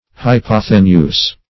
hypothenuse - definition of hypothenuse - synonyms, pronunciation, spelling from Free Dictionary
Hypothenuse \Hy*poth"e*nuse\, n.